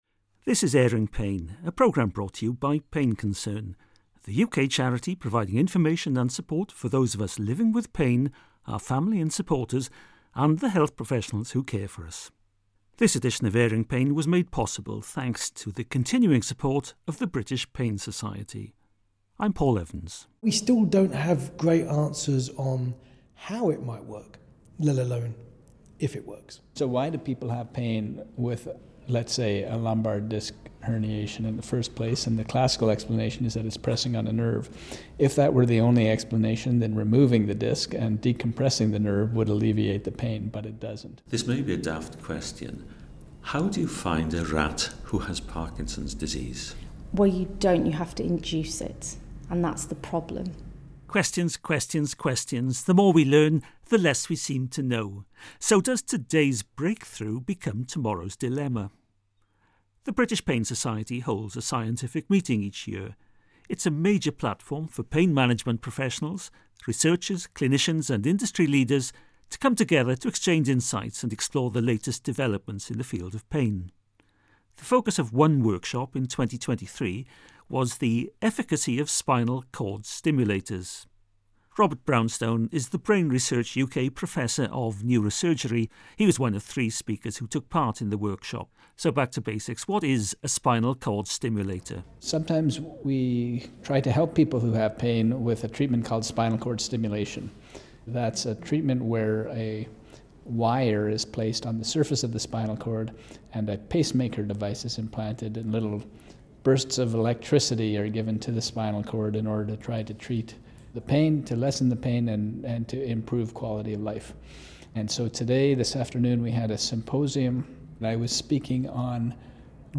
The interviews were recorded at the British Pain Society’s Annual Scientific Meeting, 2023.